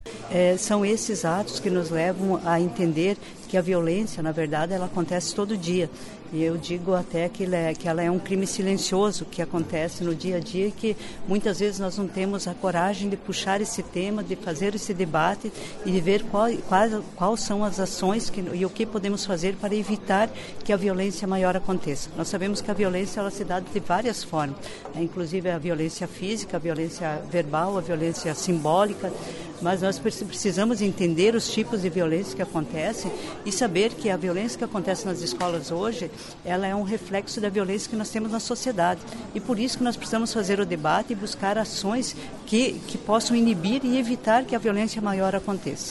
Crescimento da violência nas escolas motiva seminário na Alep